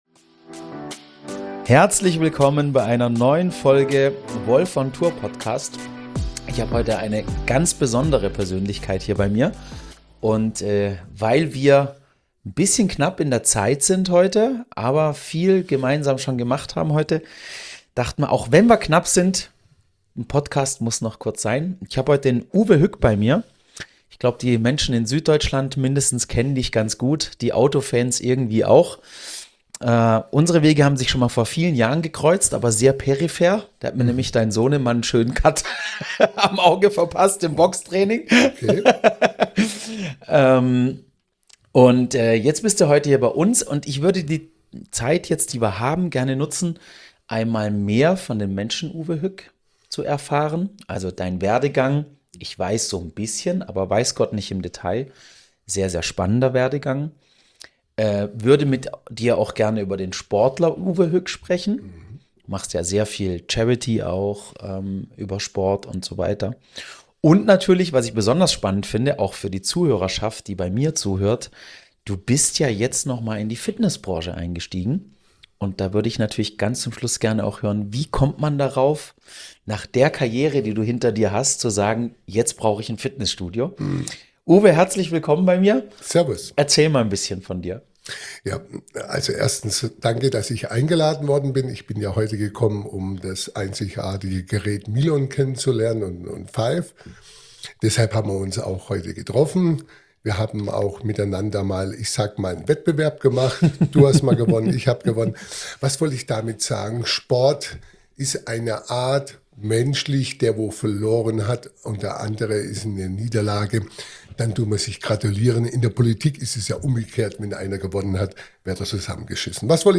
In dieser Podcast-Folge spreche ich mit Uwe Hück, ehemaliger Betriebsrat bei Porsche und Thai-Box-Europameister, über seine beeindruckende Reise aus schwierigen Verhältnissen ins Leben. Er erzählt, wie Sport ihm half, Selbstbewusstsein zu entwickeln und seinen Platz in der Gesellschaft zu finden.